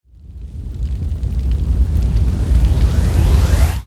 soceress_skill_fireball_01_charge.wav